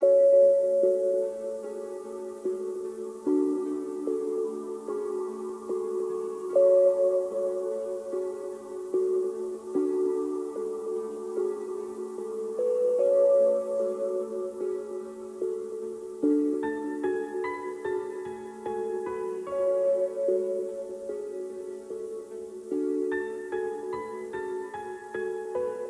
标签： 148 bpm Trap Loops Bells Loops 4.36 MB wav Key : E
声道立体声